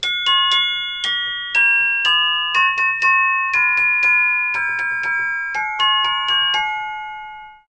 Celesta.mp3